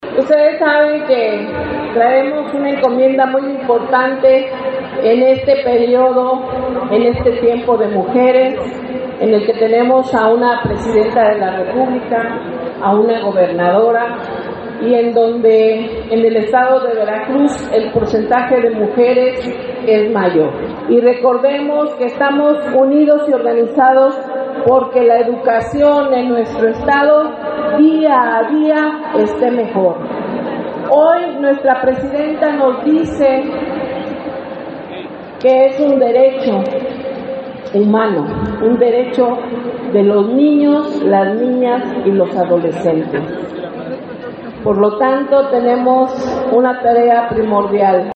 El evento se realizó en el Velódromo Internacional de Xalapa, congregando a más de dos mil trabajadores a quienes se les instó a mantenerse unidos y organizados para fortalecer la educación por amor a Veracruz.